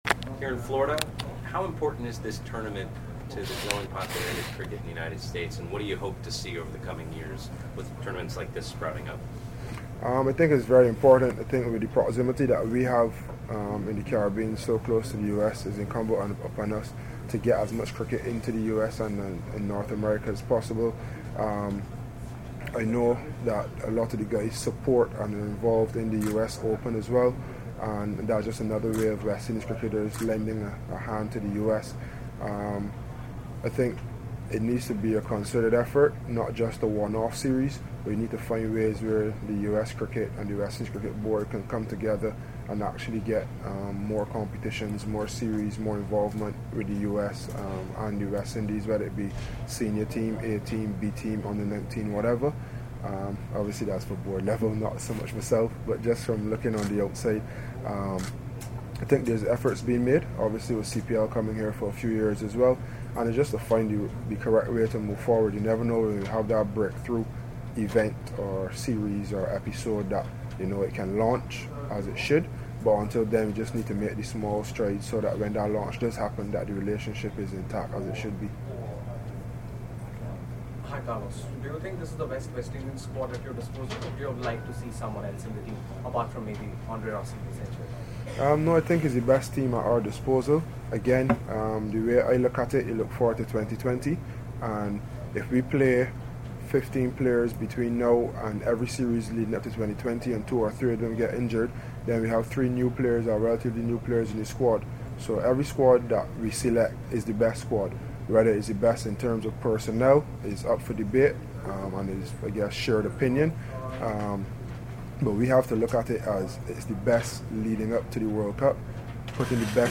West Indies captain Carlos Brathwaite spoke to members of the media ahead of the first T20 International against India.